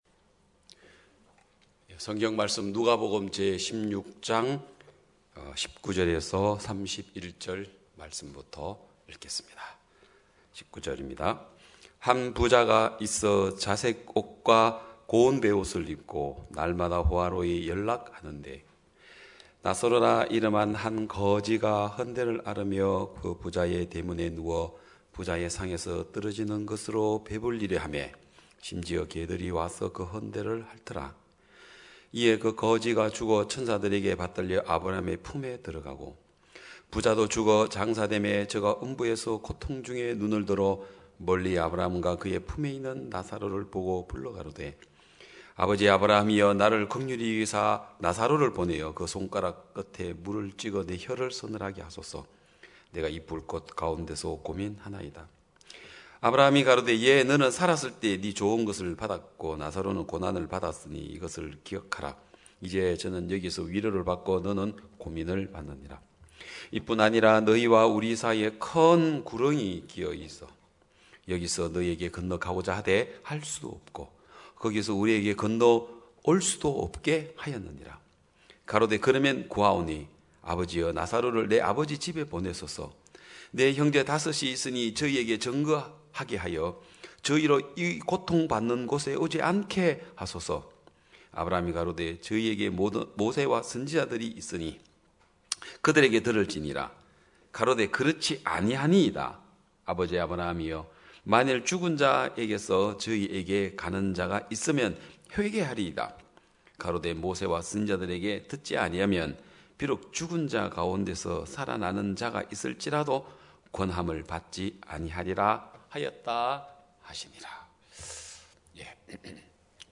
2021년 5월 30일 기쁜소식양천교회 주일오전예배
성도들이 모두 교회에 모여 말씀을 듣는 주일 예배의 설교는, 한 주간 우리 마음을 채웠던 생각을 내려두고 하나님의 말씀으로 가득 채우는 시간입니다.